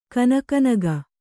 ♪ kanaka naga